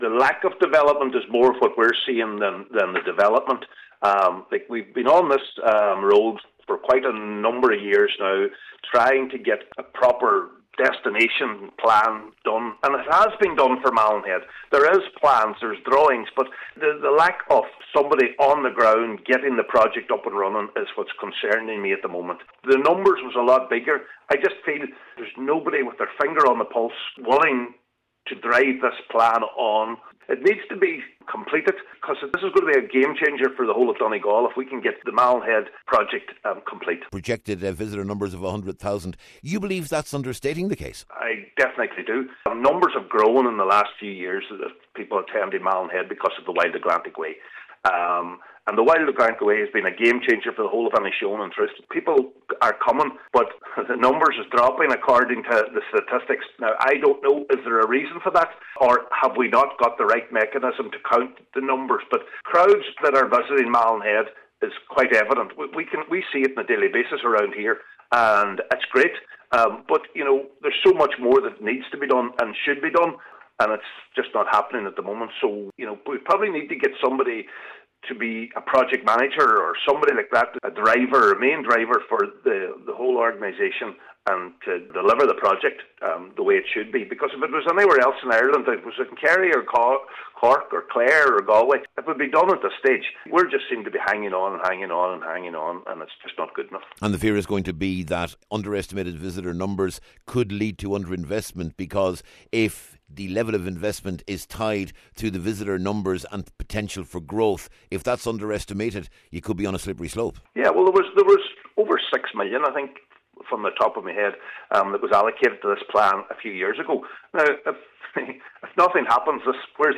Cllr Farren says there have been plans on the table and funding packages agreed for several years, and he believes what’s needed now is a central figure to drive the process forward…….